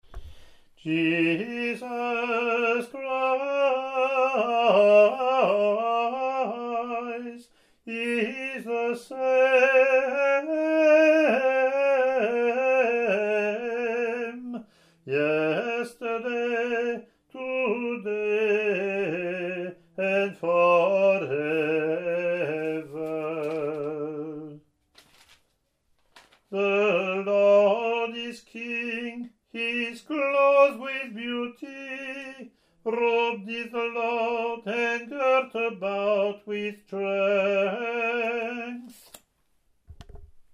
Click to hear Communion (
English antiphon+verse, Latin antiphon+ Verse)